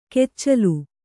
♪ keccalu